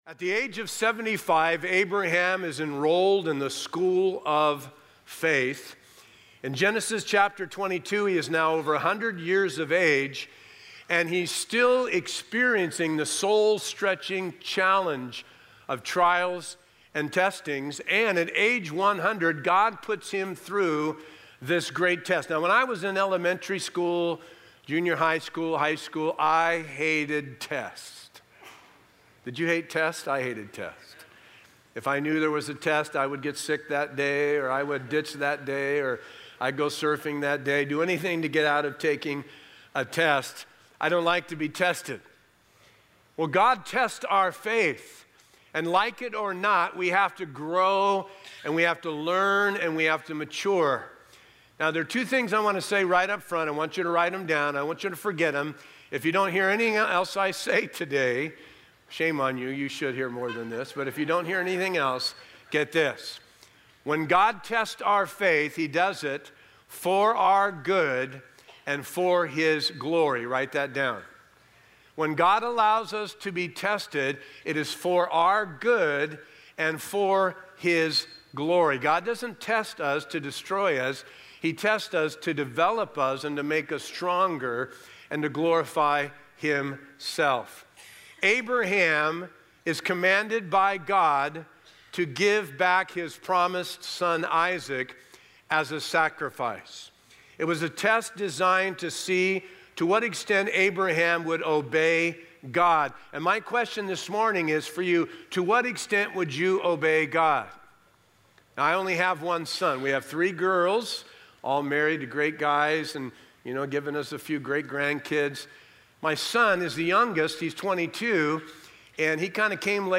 A verse-by-verse expository sermon through Genesis 22:1-24